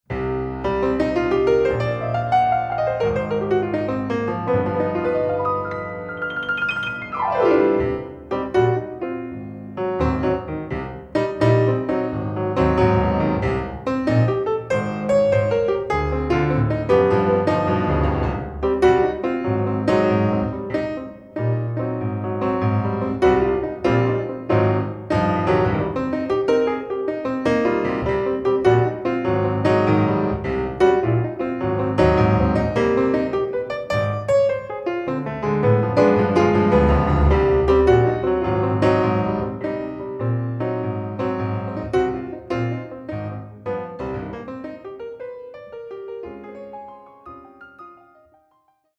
Jazz Standards & Ballads